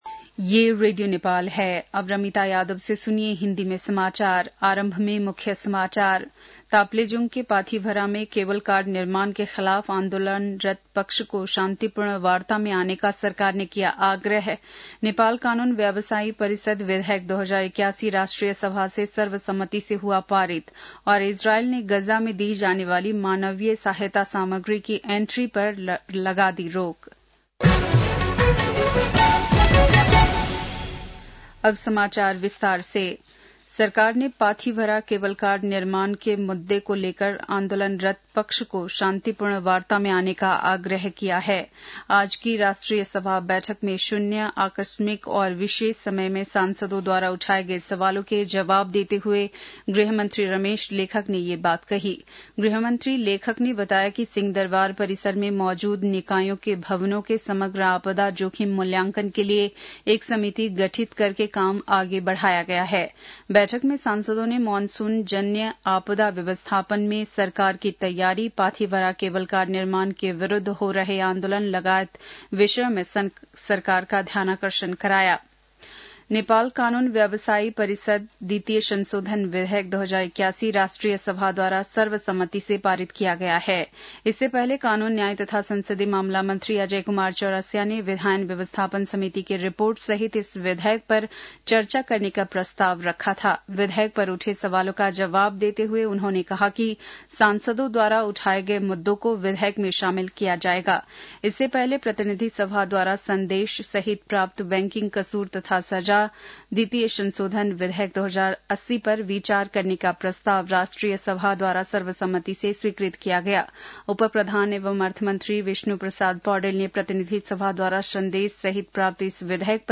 बेलुकी १० बजेको हिन्दी समाचार : १९ फागुन , २०८१
10-pm-hindi-news.mp3